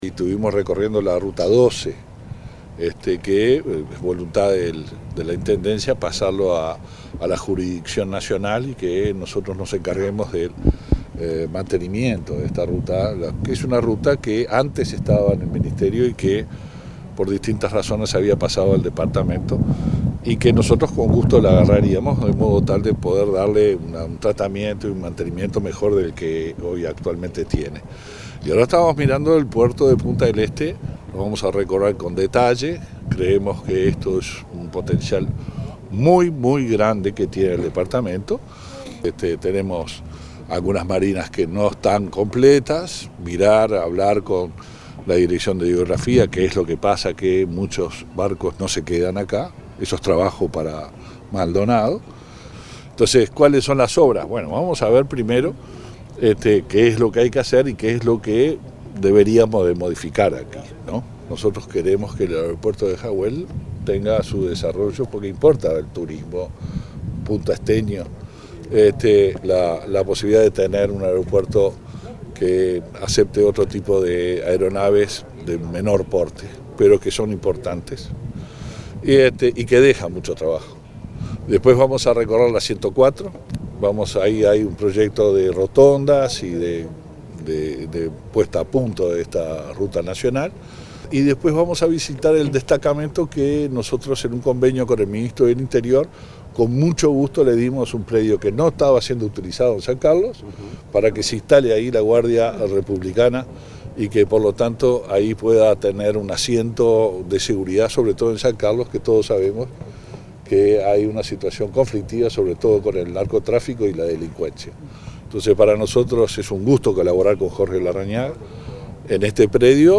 ENTREVISTA – LUIS ALBERTO HEBER – MINISTRO DE OBRAS PÚBLICAS